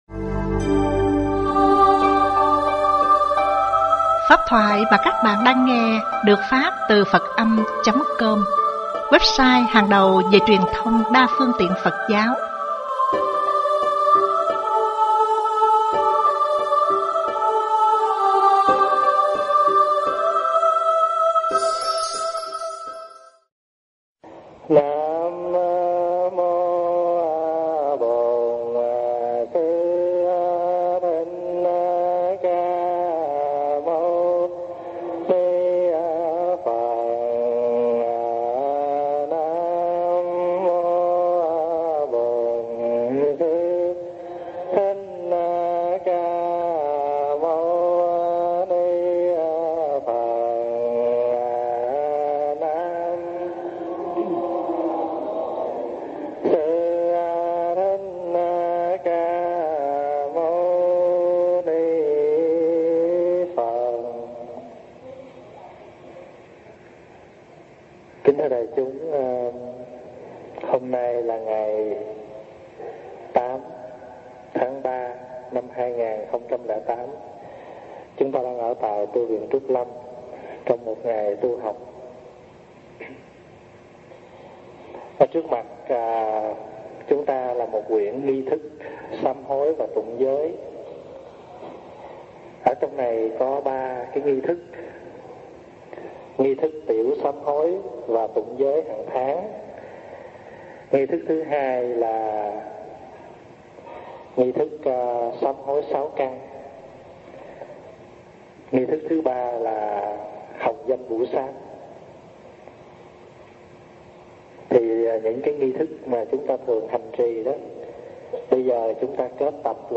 Tải mp3 thuyết pháp Làm Mới Thân Tâm